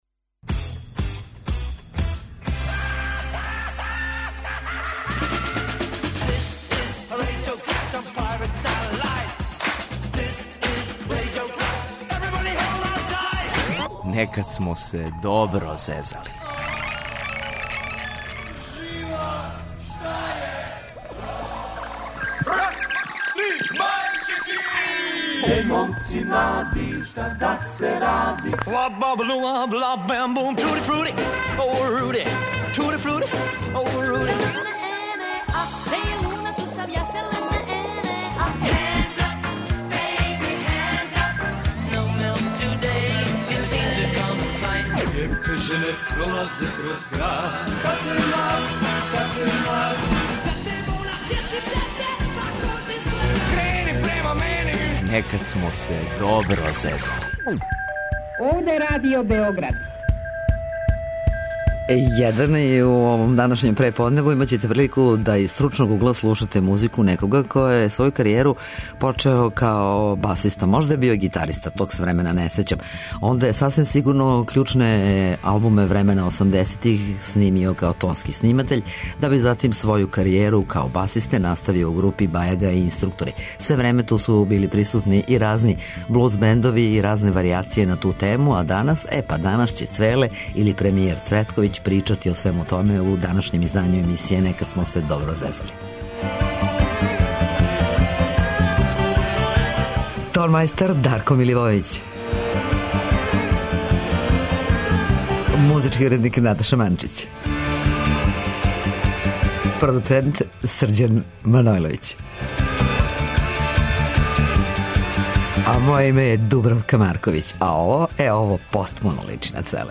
Гост емисије је бас гитариста групе 'Бајага и Инструктори' - Мирослав Цветковић Цвеле.